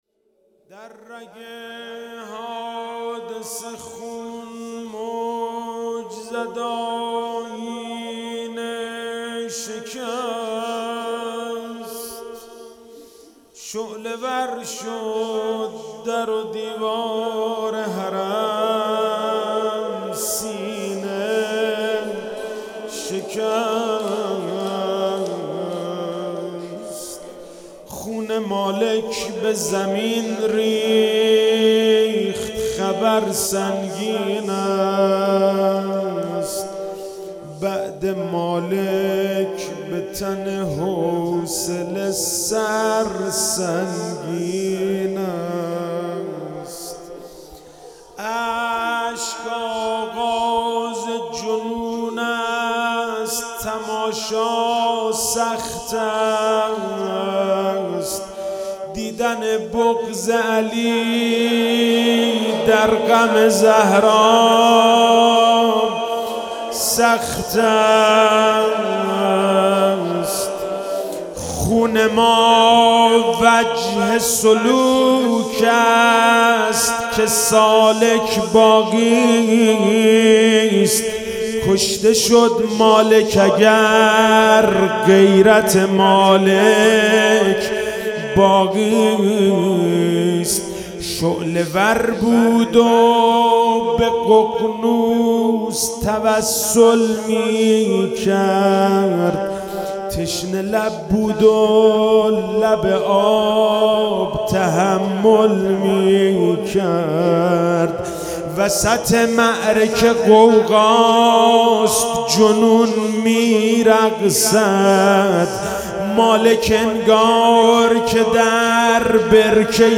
شهادت حضرت زهرا(س)
روضه - در رگ حادثه خون موج زد آیینه شکست